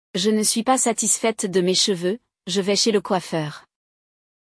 Durante o episódio, você escuta diálogos autênticos e repete as frases junto com a professora.